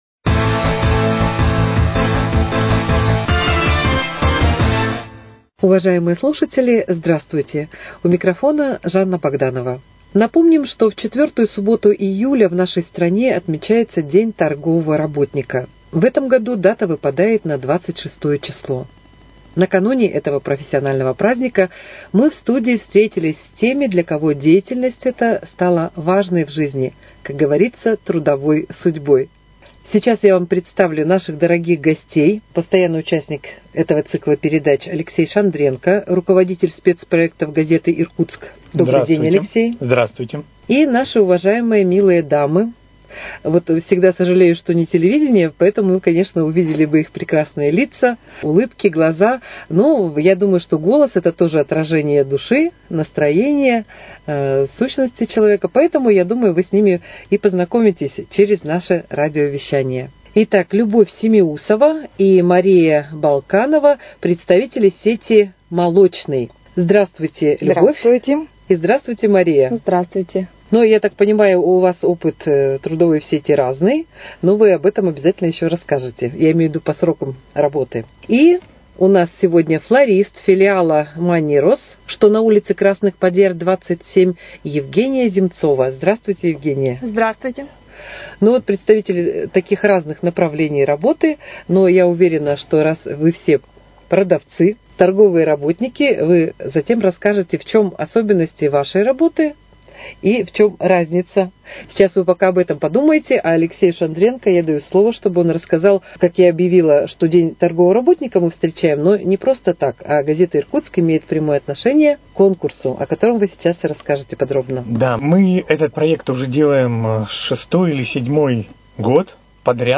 Актуальное интервью: Передача ко Дню торгового работника